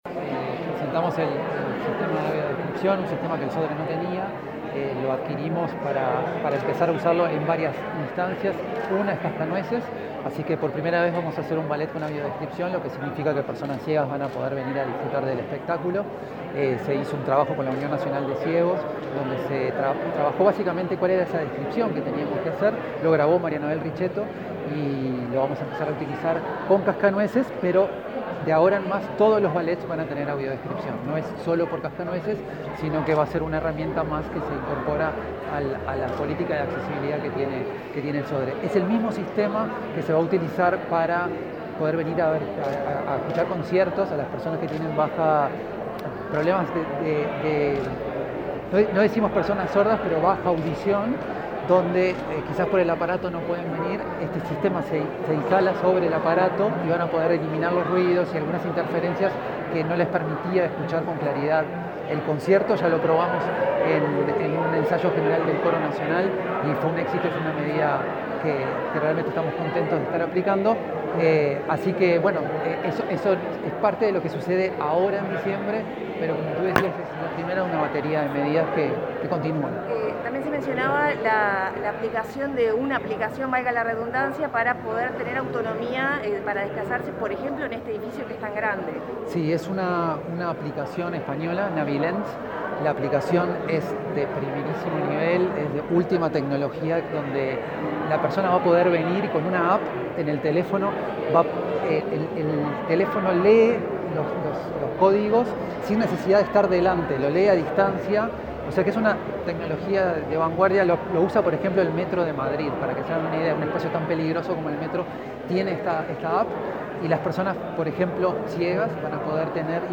Declaraciones a la prensa del presidente del Sodre, Martín Inthamoussú